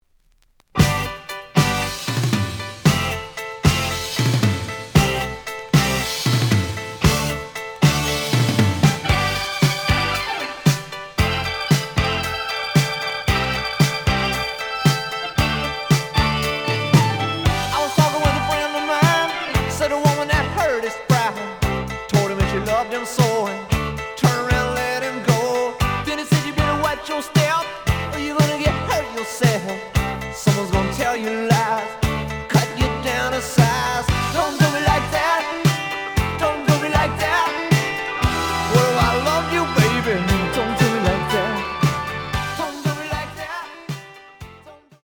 試聴は実際のレコードから録音しています。
●Genre: Rock / Pop
●Record Grading: VG+~EX- (盤に若干の歪み。多少の傷はあるが、おおむね良好。)